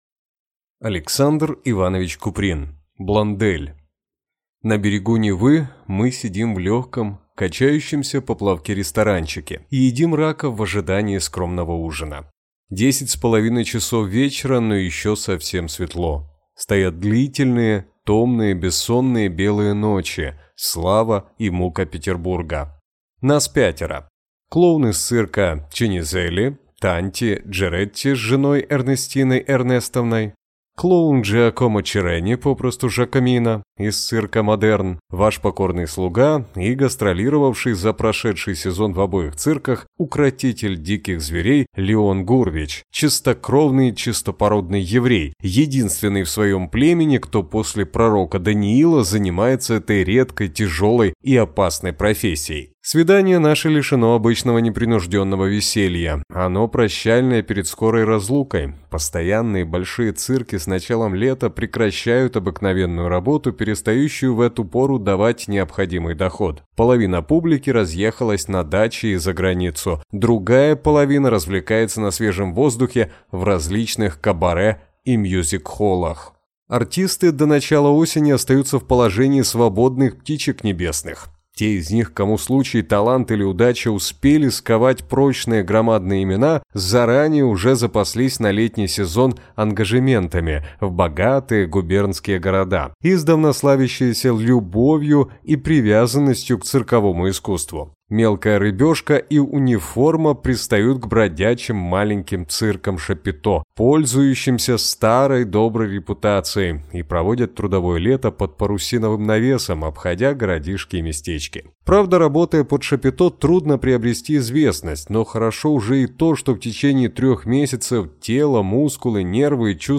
Аудиокнига Блондель | Библиотека аудиокниг
Читает аудиокнигу